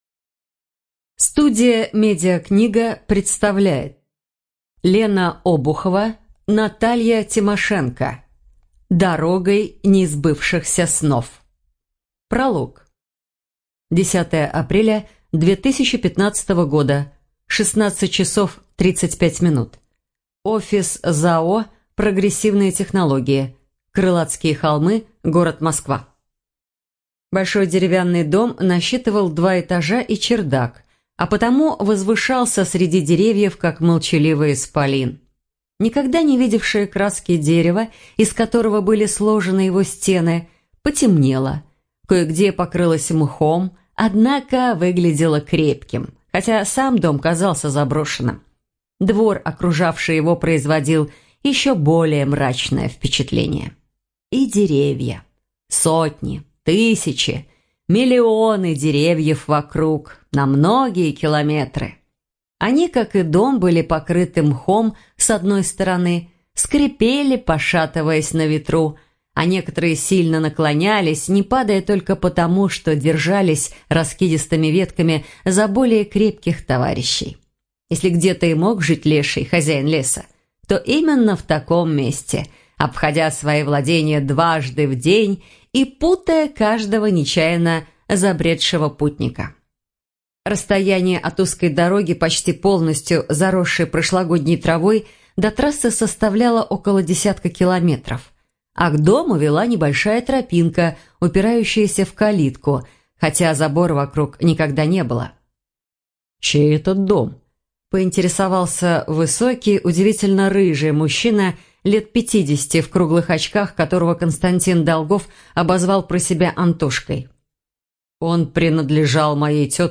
ЖанрДетективы и триллеры
Студия звукозаписиМедиакнига